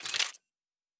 Weapon 08 Foley 1 (Laser).wav